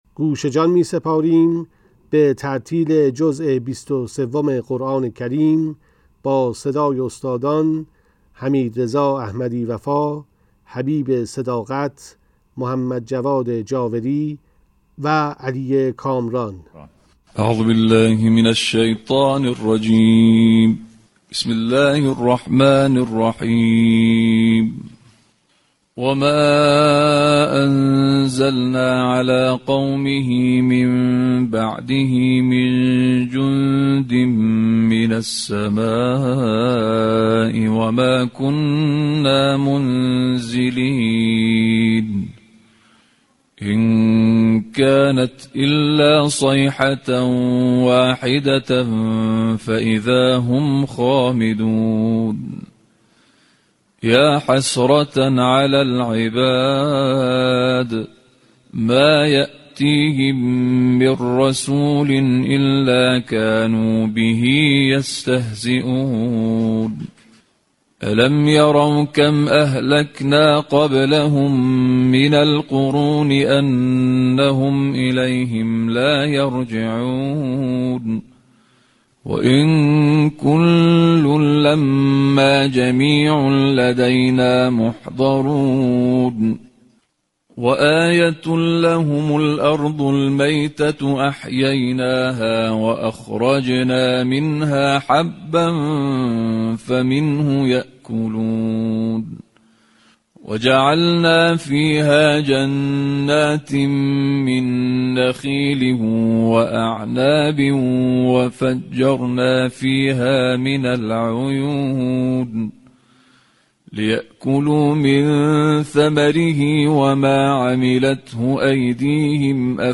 قرائت ترتیل جزء بیست و سوم قرآن با صدای قاریان بین‌المللی + صوت
نسخه باکیفیت تلاوت جزء بیست و سوم قرآن با صدای قاریان بین‌المللی